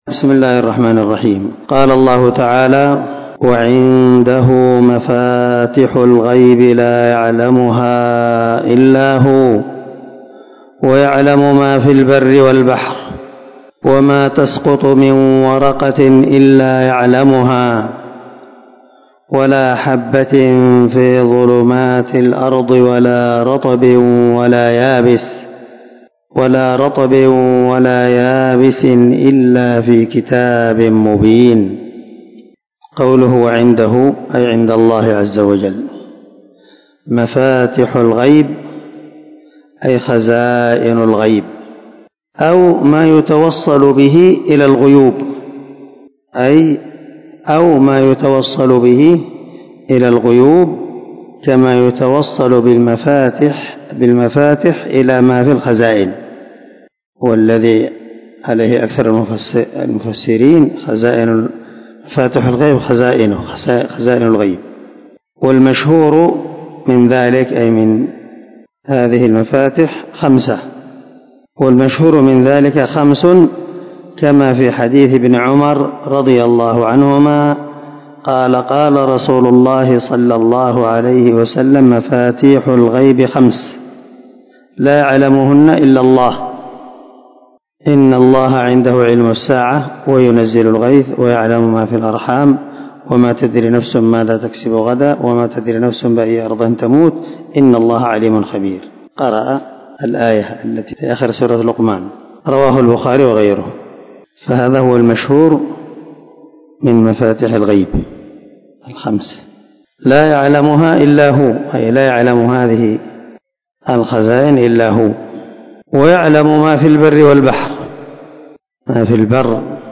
409الدرس 17 تفسير آية ( 59 ) من سورة الأنعام من تفسير القران الكريم مع قراءة لتفسير السعدي